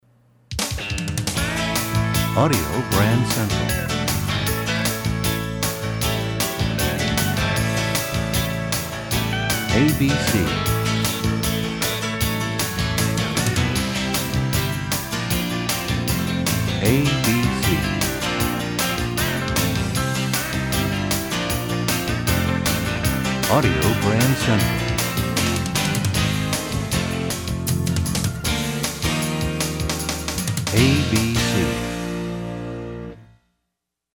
Genre: Jingles.